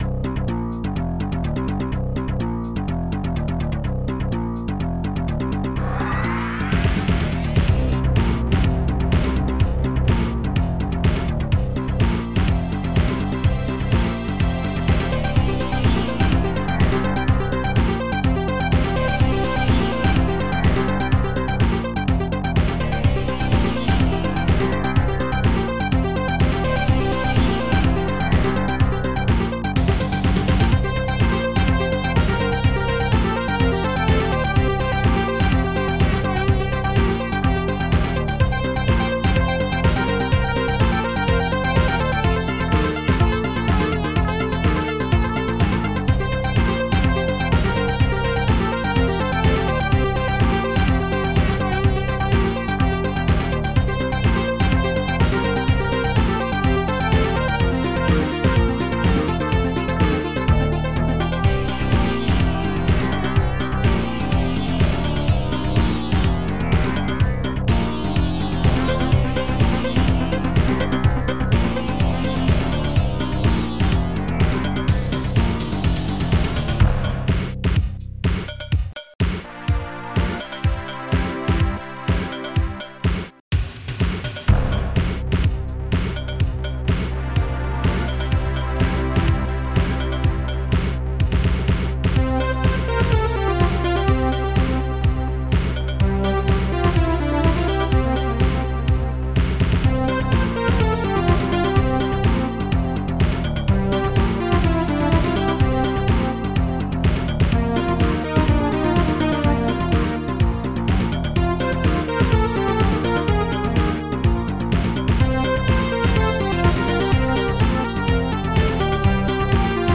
Protracker and family
st-08:hihat34
st-07:cymbal2
st-07:fatsnare
st-24:neutron-synth
st-08:slagbrass